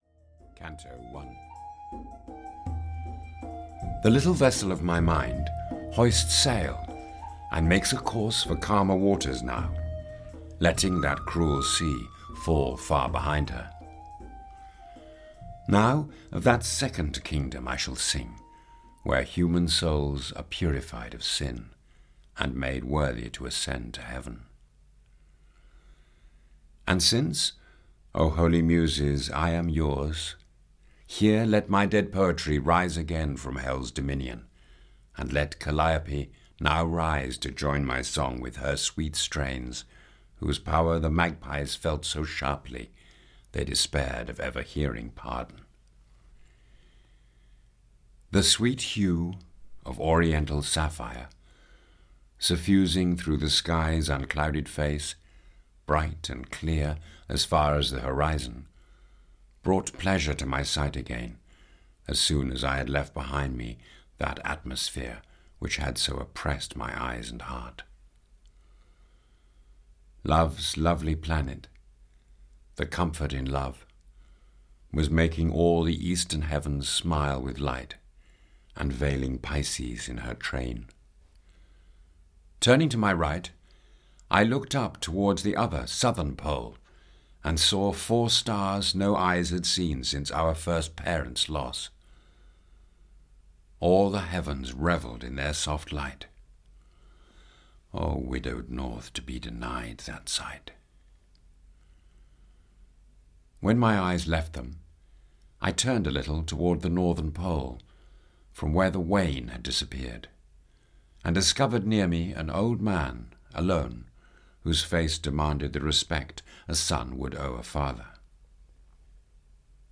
Purgatory (EN) audiokniha
Ukázka z knihy
• InterpretHeathcote Williams